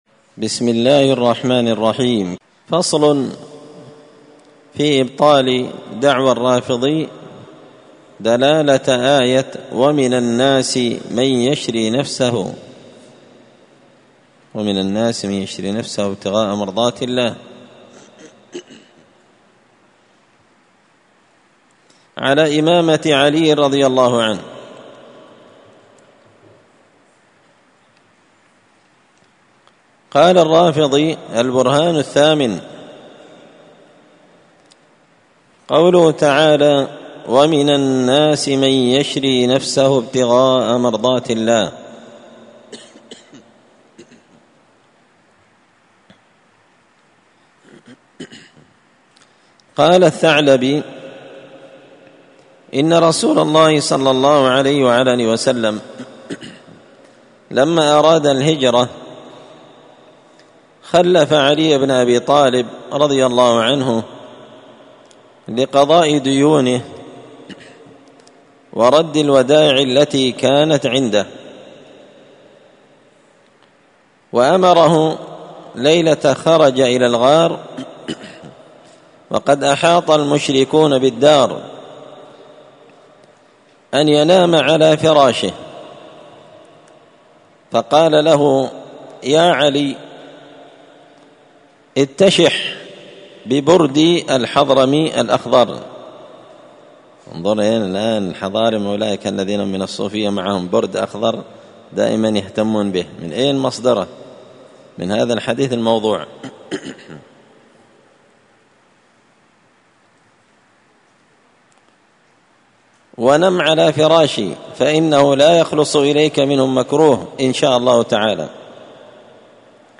الأربعاء 29 محرم 1445 هــــ | الدروس، دروس الردود، مختصر منهاج السنة النبوية لشيخ الإسلام ابن تيمية | شارك بتعليقك | 79 المشاهدات